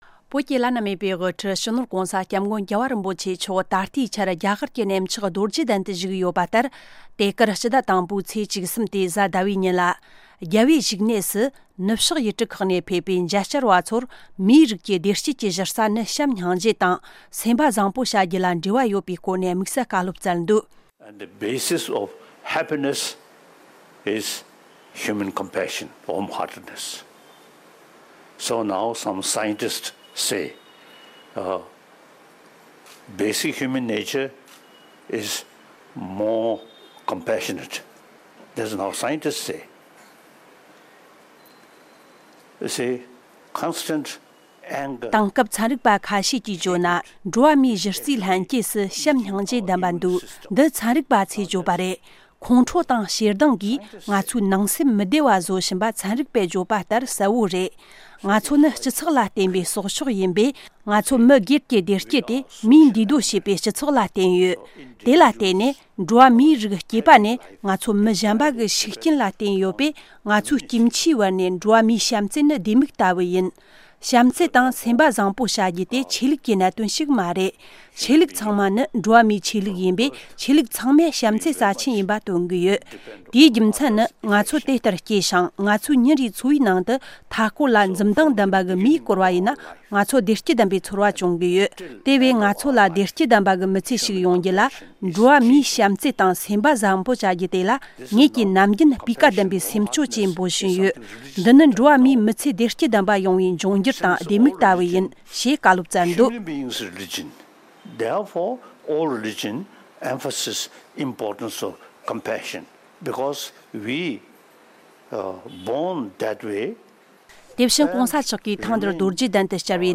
ནུབ་ཕྱོགས་པར་བསྩལ་བའི་བཀའ་སློབ།
༧གོང་ས་མཆོག་གིས་རྒྱ་གར་གནས་མཆོག་རྡོ་རྗེ་གདན་དུ་ནུབ་ཕྱོགས་ཡུལ་གྲུ་ཁག་ནས་ཕེབས་པའི་མཇལ་བ་ཚོར་མི་ཚེ་བདེ་སྐྱིད་ལྡན་པ་ཡོང་ཐབས་སྐོར་ལ་ལམ་སྟོན་གནང་སྟེ། འགྲོ་བ་མིའི་ལྷན་སྐྱེས་སུ་བྱམས་སྙིང་རྗེ་ལྡན་པ་ཚན་རིག་པ་ཚོས་གསལ་སྟོན་བྱས་པ་ལྟར་དེ་ལག་ལེན་བསྟར་རྒྱུ་ནི་བདེ་སྐྱིད་ཀྱི་ལྡེ་མིག་ལྟ་བུ་ཡིན་ཞེས་བཀའ་བསྩལ་ཡོད་ཅིང་།